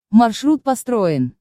Звуки навигатора
Маршрут проложен